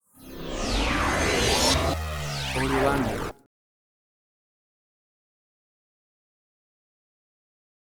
WAV Sample Rate: 16-Bit stereo, 44.1 kHz